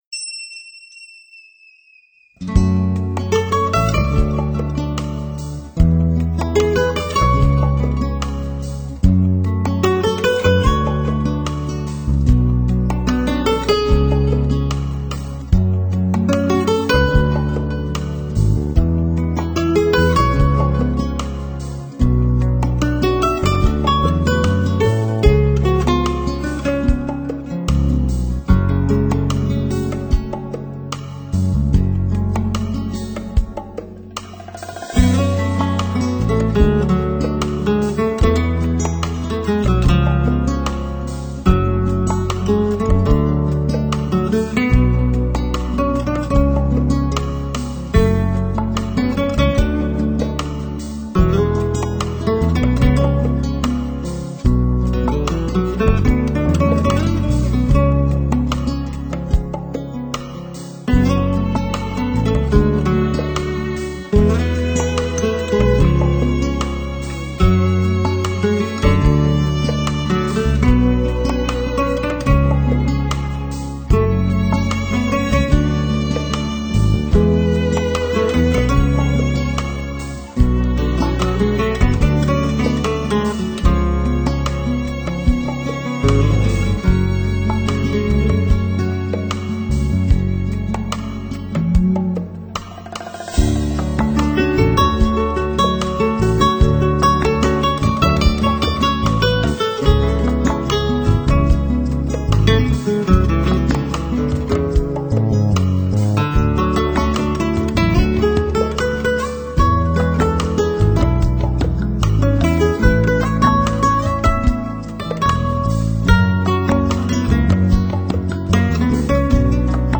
他丰富的音乐风格包括了夏威夷、新佛朗明哥、拉丁、吉普赛，配合上一点键盘及打击乐器。